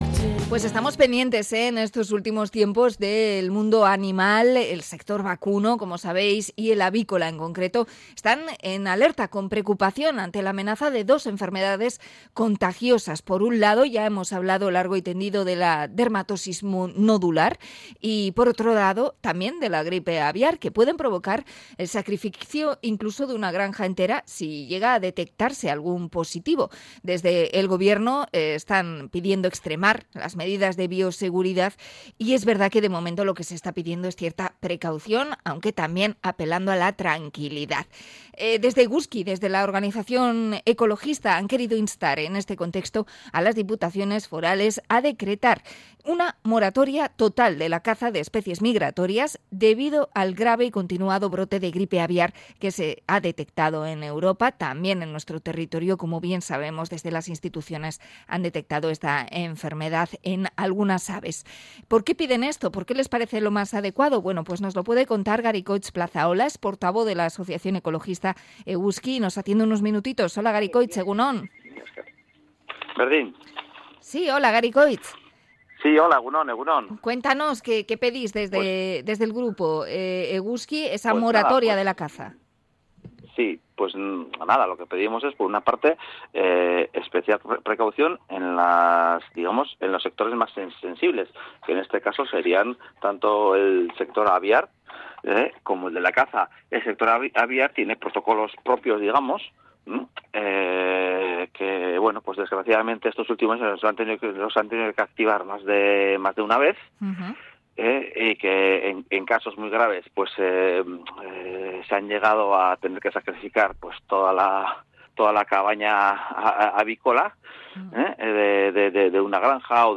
Entrevista a la asociación Eguzki por la gripe aviar
int.-eguzki-gripe-aviar.mp3